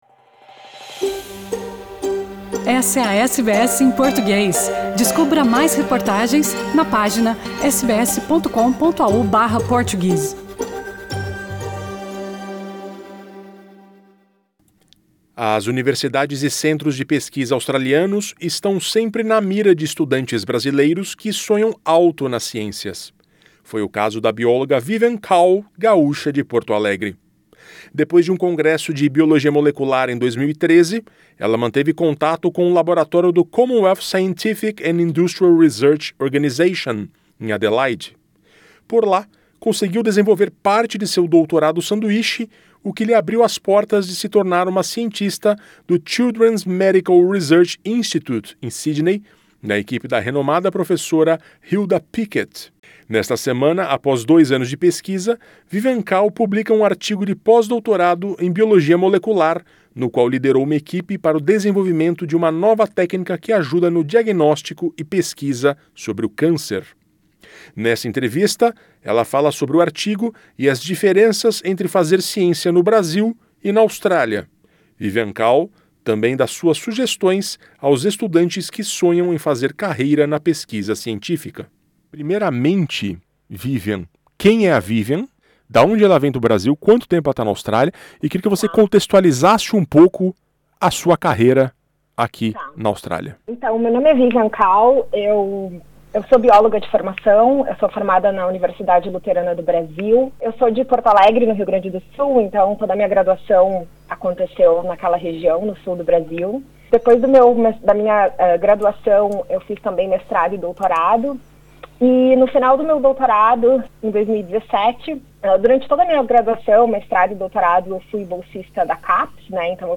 Nesta entrevista, ela fala sobre o artigo e as diferenças entre fazer ciência no Brasil e na Austrália.